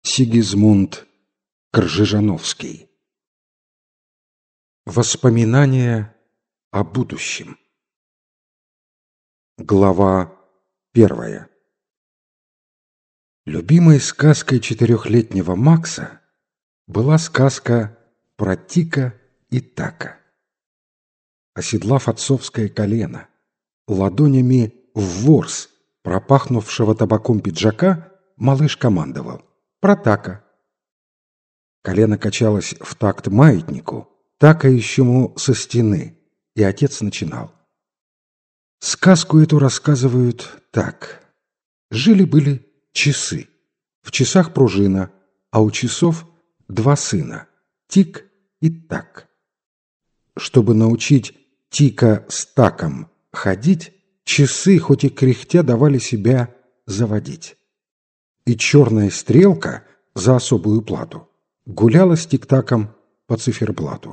Аудиокнига Воспоминания о будущем | Библиотека аудиокниг